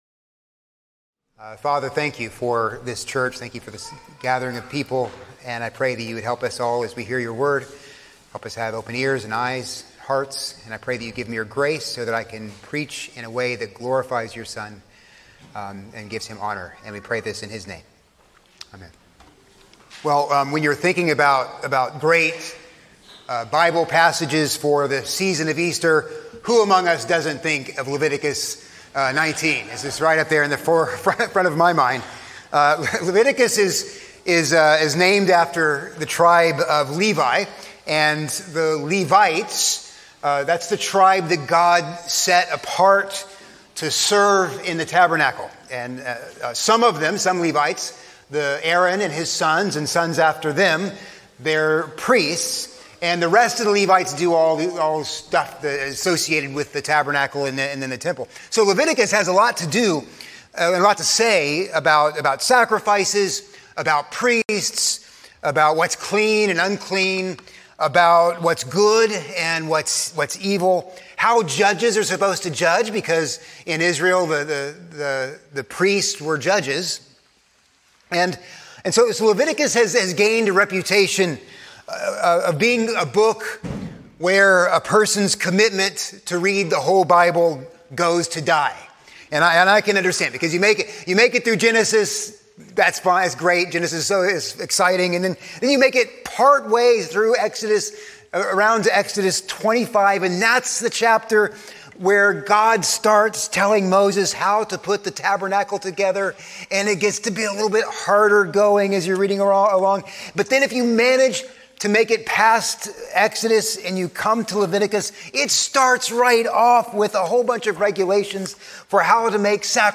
A sermon on Leviticus 19:1-2, 9-18